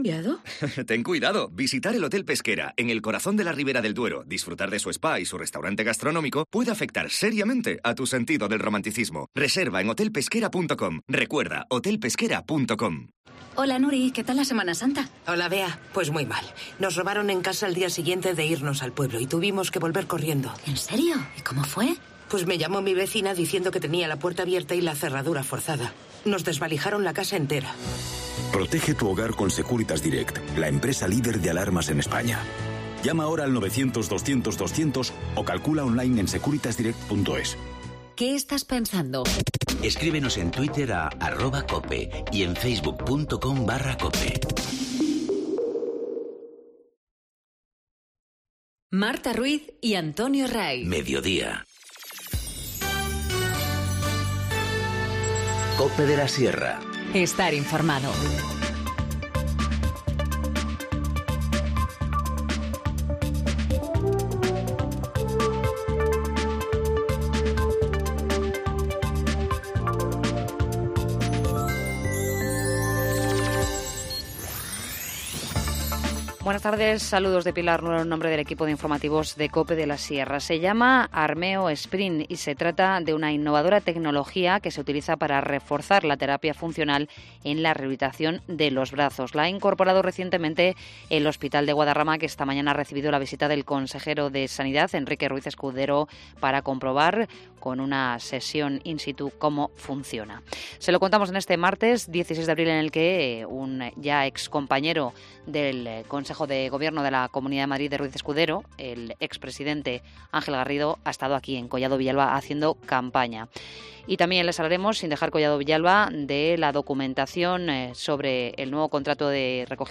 Informativo Mediodía 16 abril 14:20h